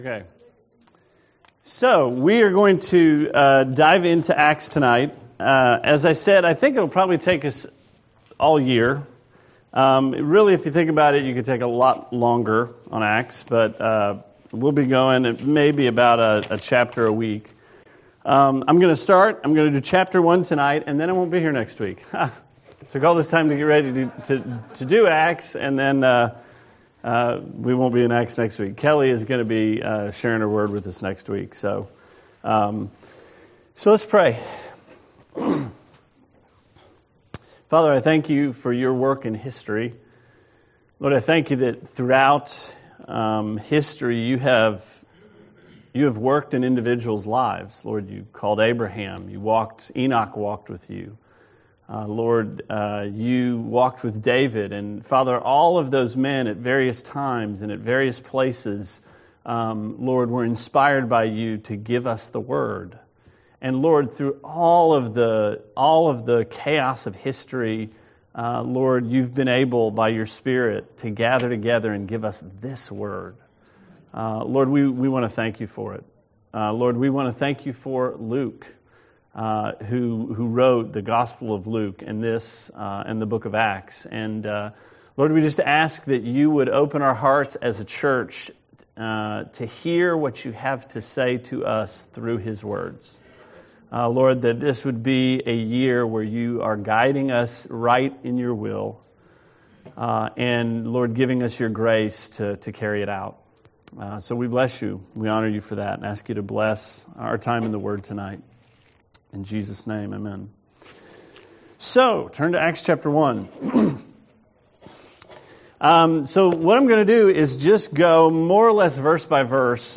Sermon 2/12: Acts 1 – Trinity Christian Fellowship